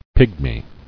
[Pig·my]